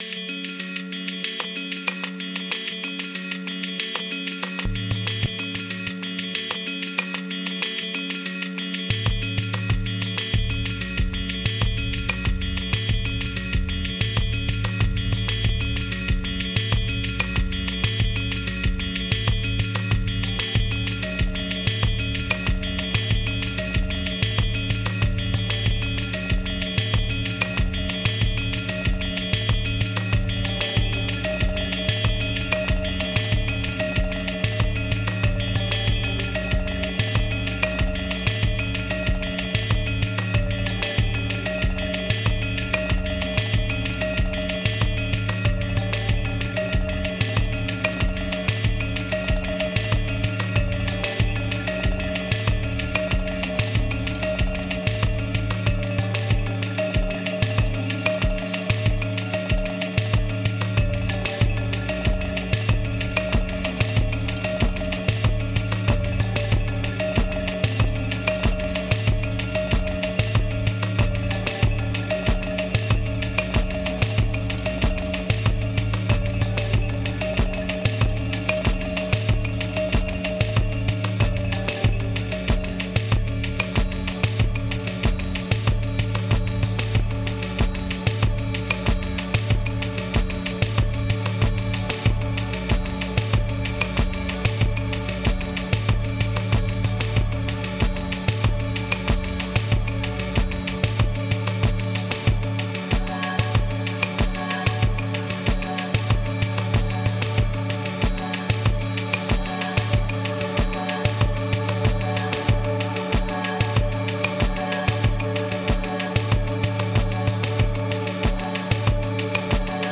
Lost around delays.